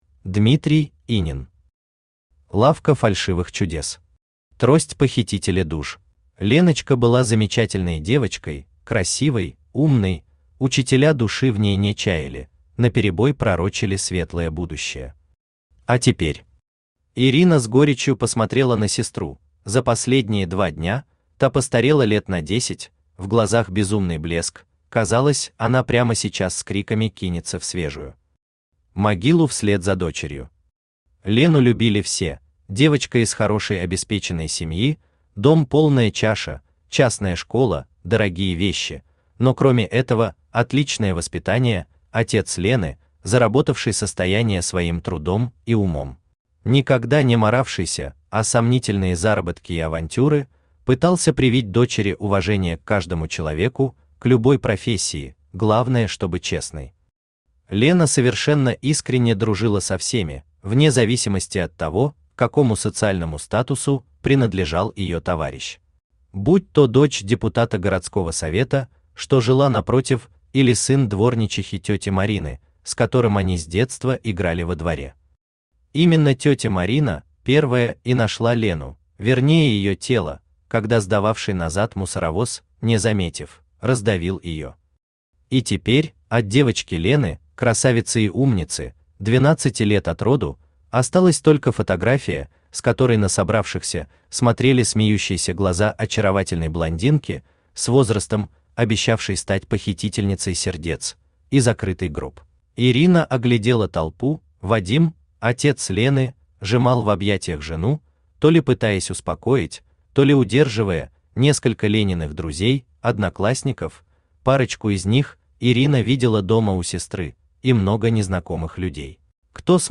Аудиокнига Лавка фальшивых чудес. Трость похитителя душ | Библиотека аудиокниг
Трость похитителя душ Автор Дмитрий Инин Читает аудиокнигу Авточтец ЛитРес.